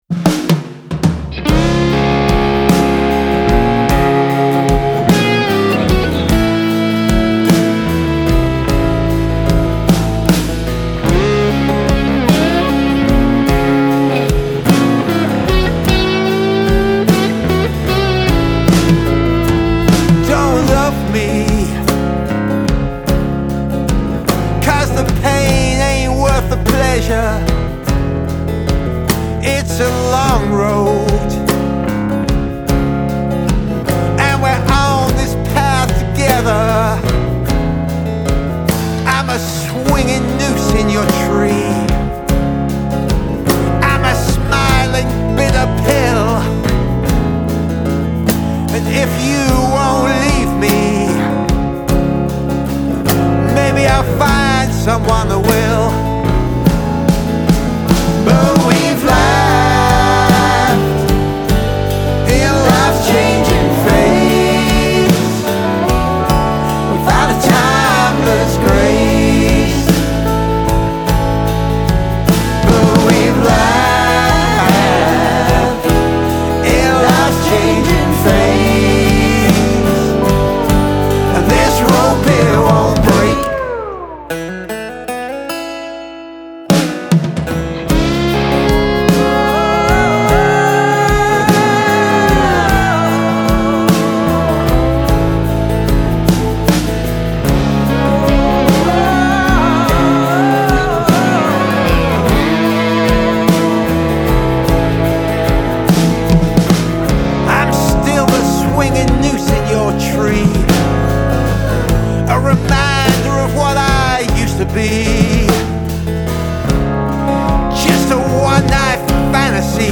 Male Vocal, Guitar, Bass Guitar, Drums